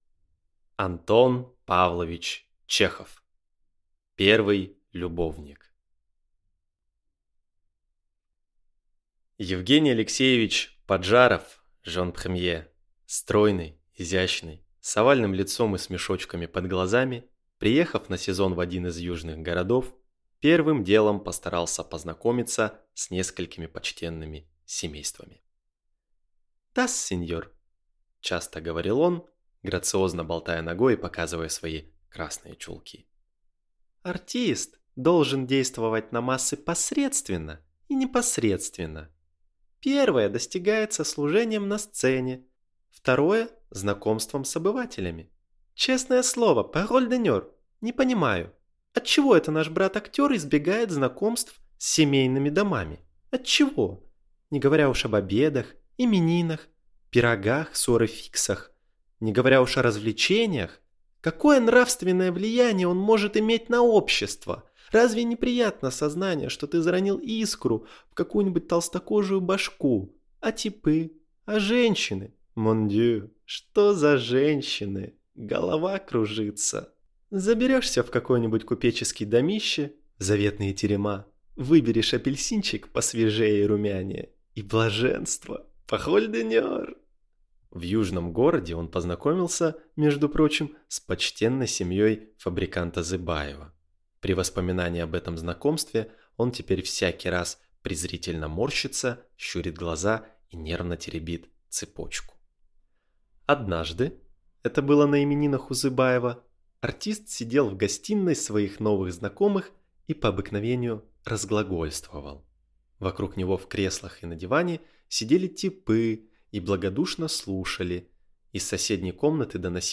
Аудиокнига Первый любовник | Библиотека аудиокниг